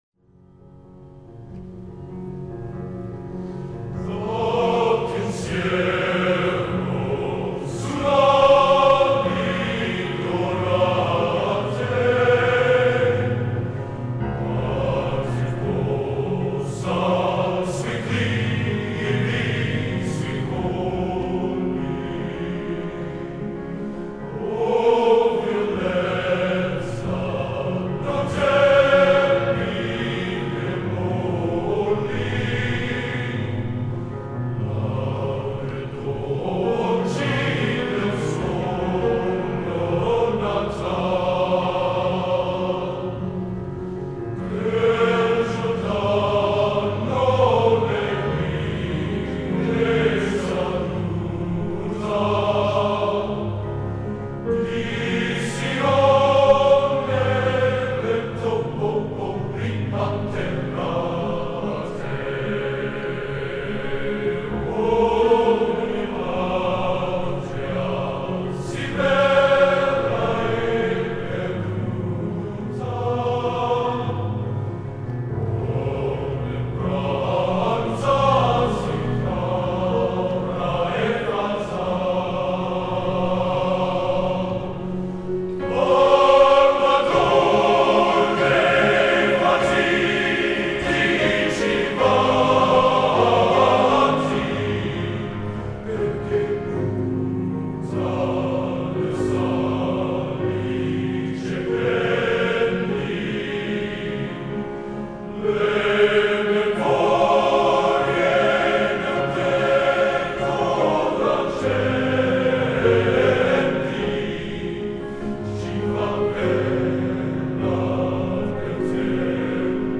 Location: West Lafayette, Indiana
Genre: Opera | Type: